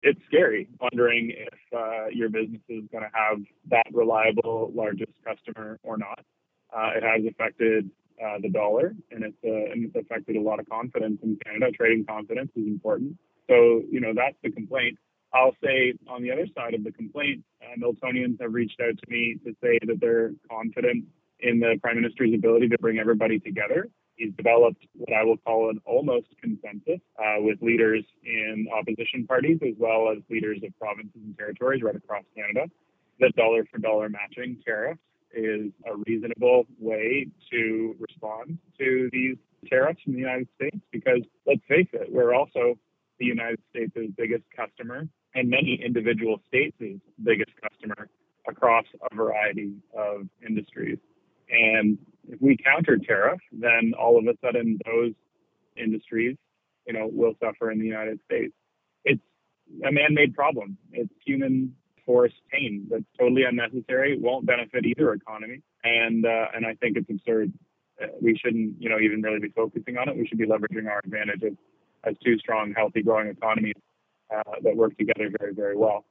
Hear from Milton MP Adam van Koeverden on the tariffs in the original article below: